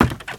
STEPS Wood, Creaky, Run 12.wav